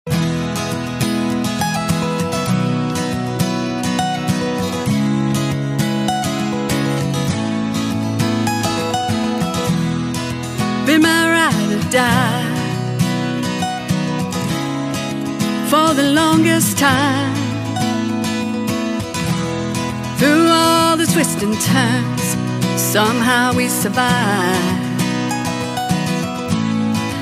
Here is a sample of the beautiful song: